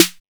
pcp_snare03.wav